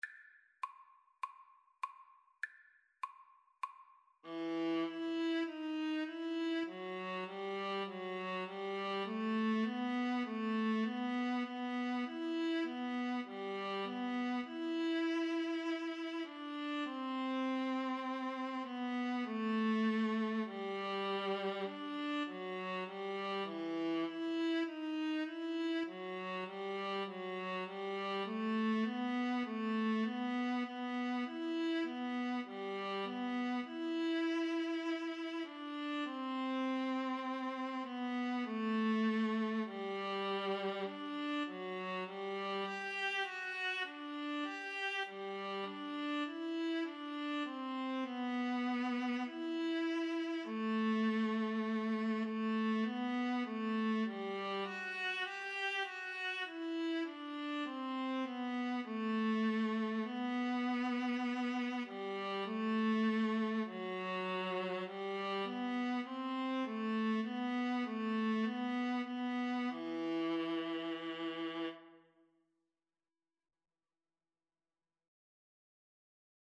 Viola 1Viola 2
Moderato
4/4 (View more 4/4 Music)
Viola Duet  (View more Easy Viola Duet Music)
Classical (View more Classical Viola Duet Music)